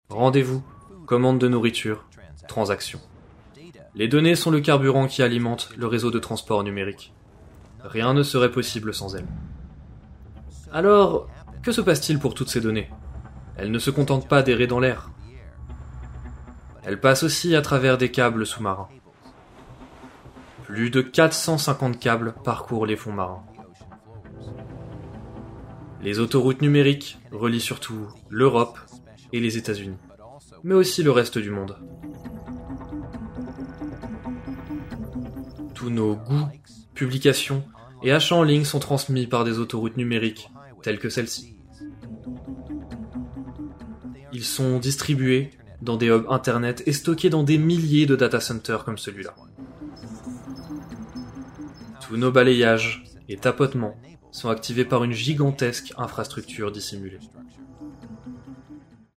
Voix Off/Voice Over Documentaire en Français